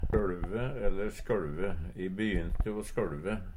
DIALEKTORD PÅ NORMERT NORSK sjøLve skjelve Infinitiv Presens Preteritum Perfektum sjøLve sjøLv skalv sjølve Eksempel på bruk E bynte o sjøLve.